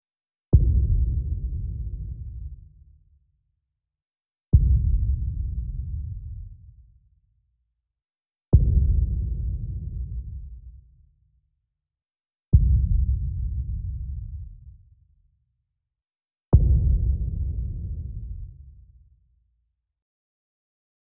Download Boom sound effect for free.
Boom